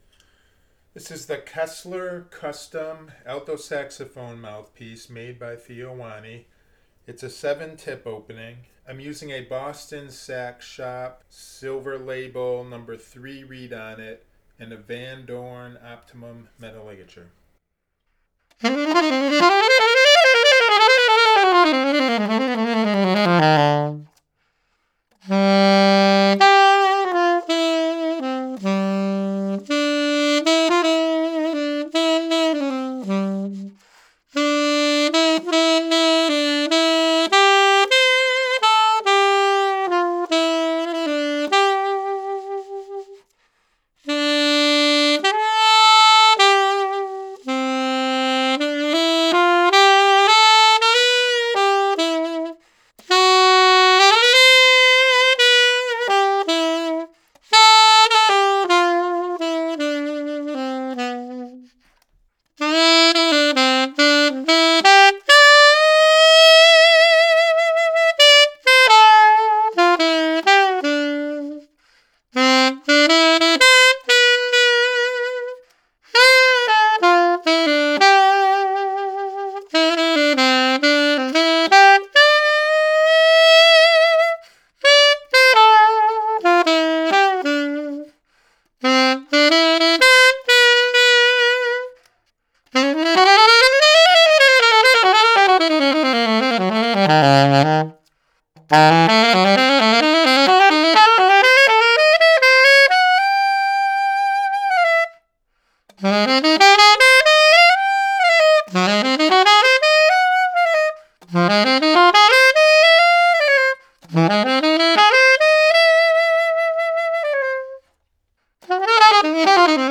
The articulation was clean and crisp and the Kessler Custom NY7 alto saxophone mouthpiece performed well on fast jazz lines while applying articulation.
The second sound clip is the same as the first clip with no reverb added to the mix.  It is a “dry” recording meaning no effects have been added at all.
Kessler Custom NY7 Alto Saxophone Mouthpiece by Theo Wanne – Same Clip as Above with No Reverb Added-BSS (Boston Sax Shop) #3 Silver Label Reed